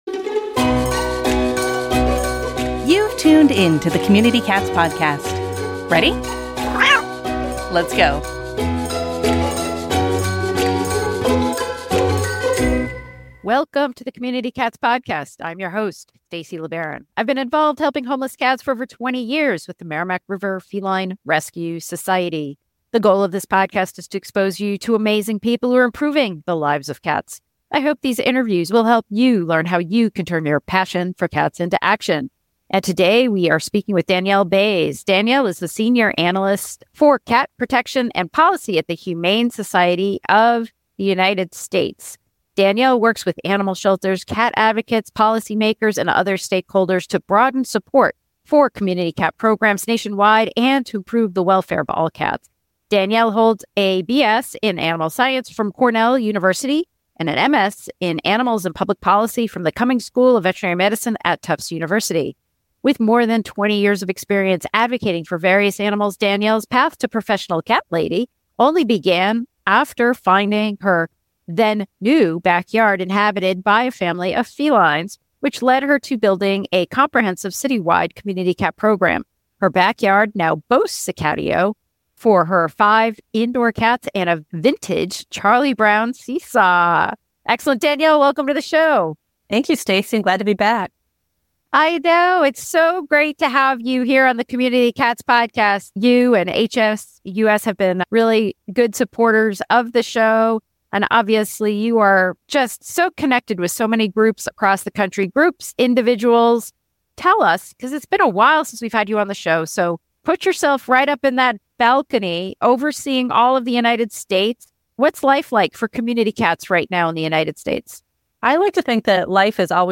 Join in an engaging conversation highlighting the resilience and adaptability of cats and the dedicated people working to improve their lives.